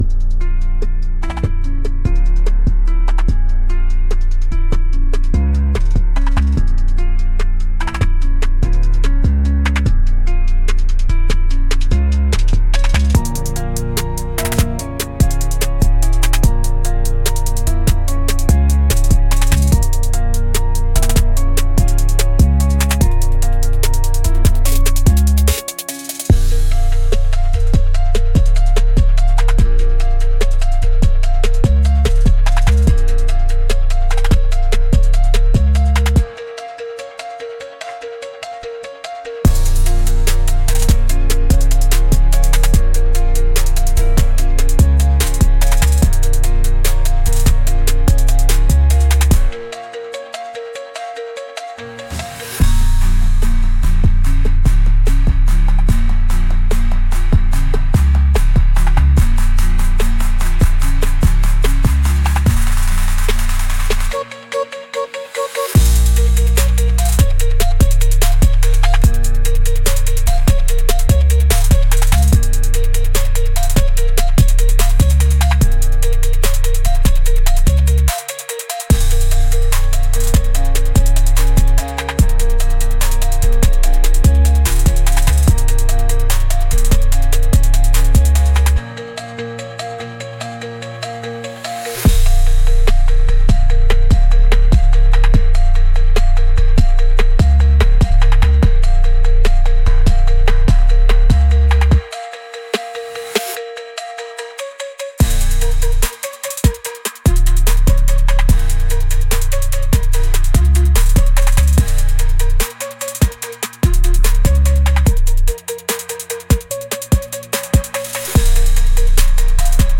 Instrumental - Chrono-Scales